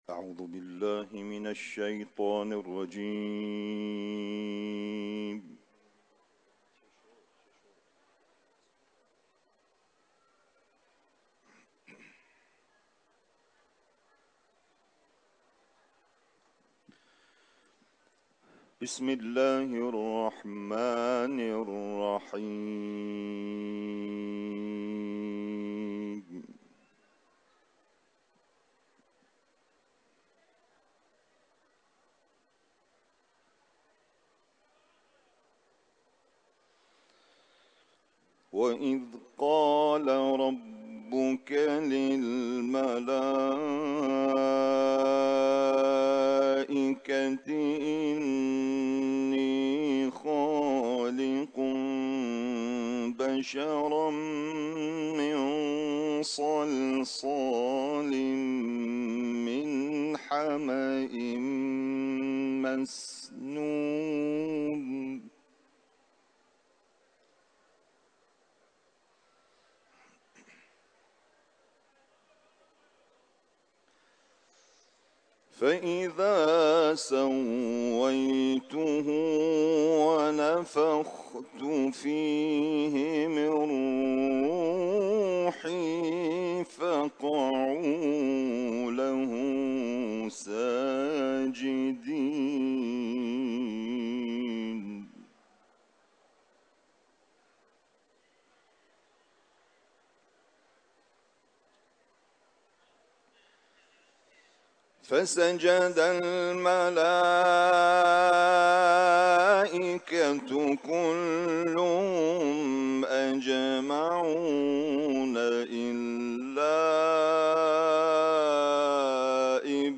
İranlı kâri
Kuran tilaveti